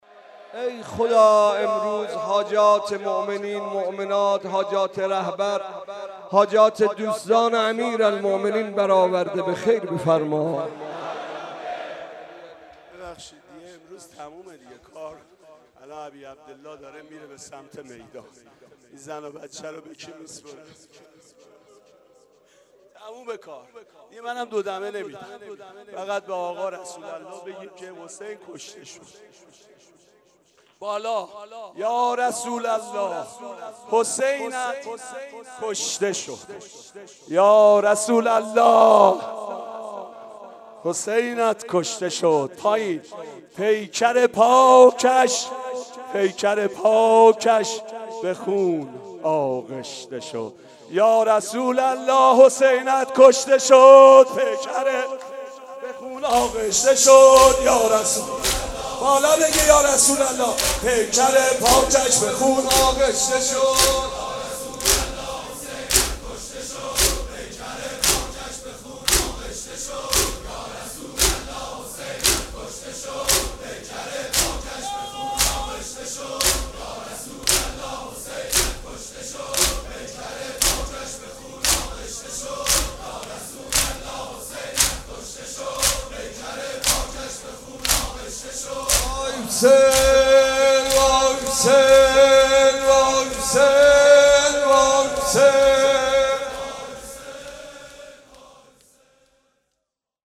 مناسبت : عاشورای حسینی
قالب : دو دمه